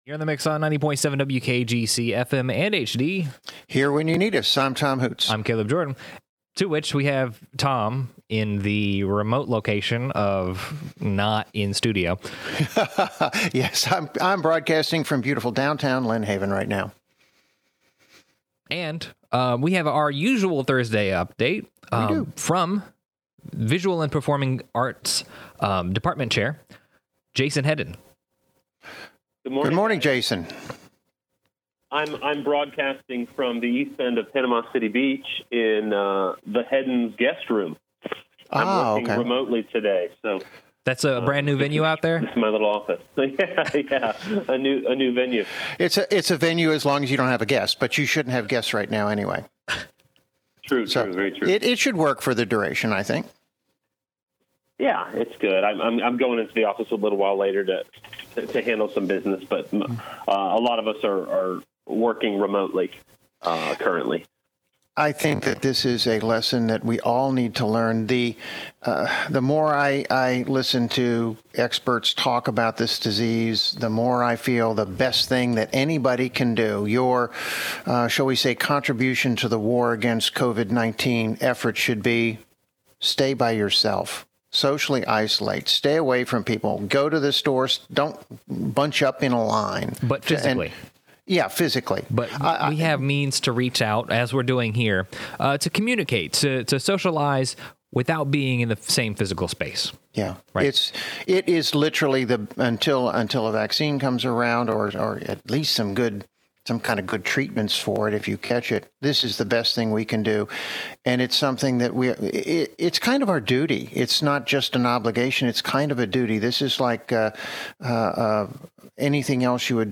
Morning Mix Interview